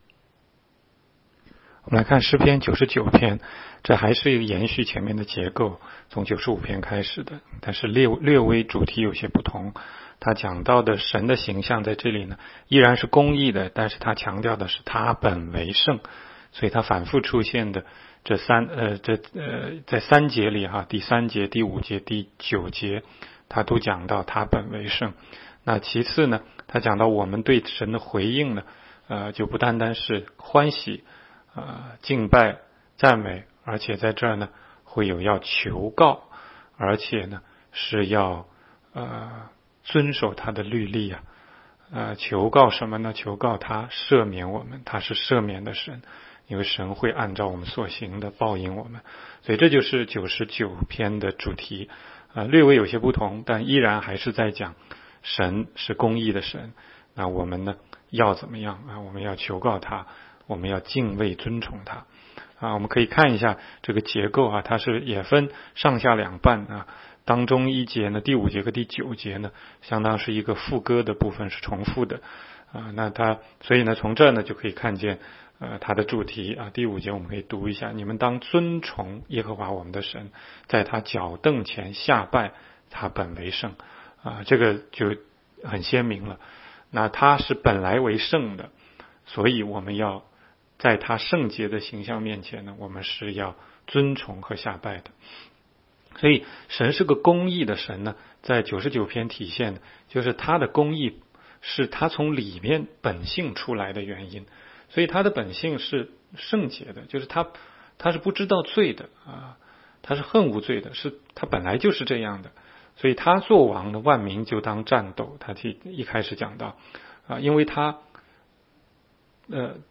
16街讲道录音 - 每日读经-《诗篇》99章